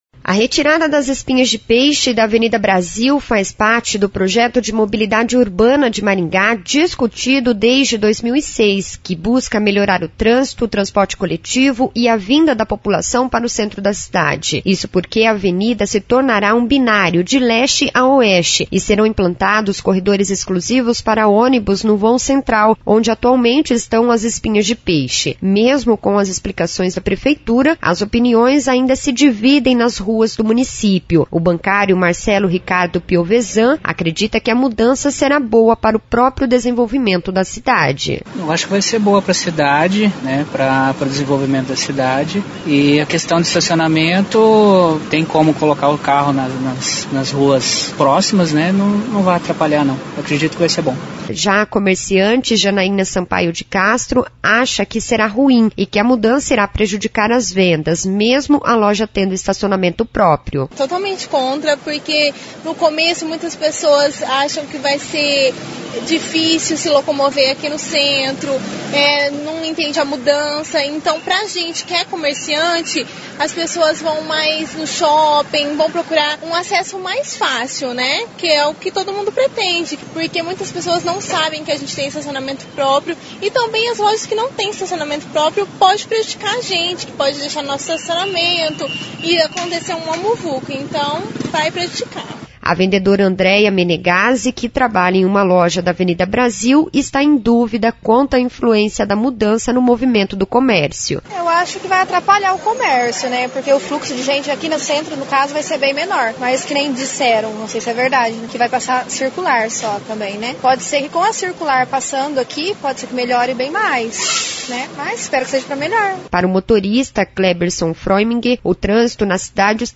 Nas ruas, a reportagem da CBN encontrou pessoas a favor, contra e indecisas